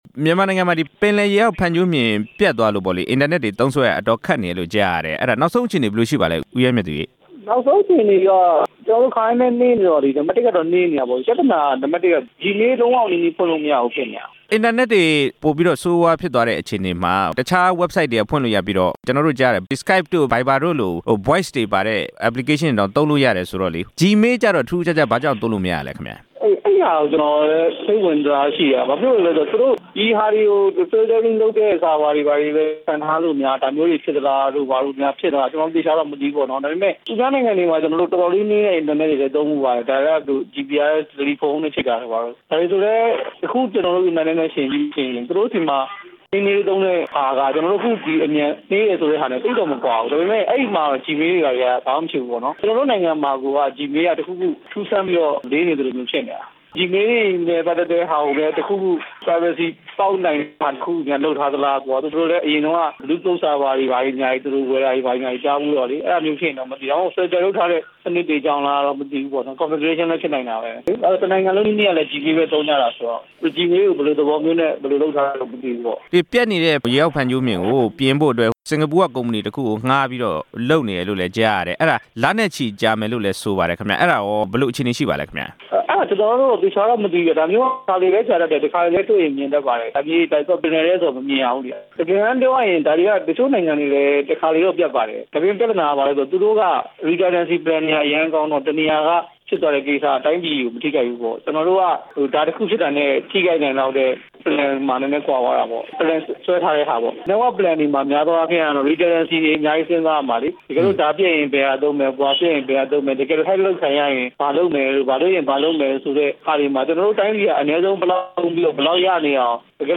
အင်တာနက်နှေးကွေးမှုနဲ့ ပတ်သက်လို့ ကွန်ပြူတာ နည်းပညာရှင်နဲ့ ဆက်သွယ်မေးမြန်းချက်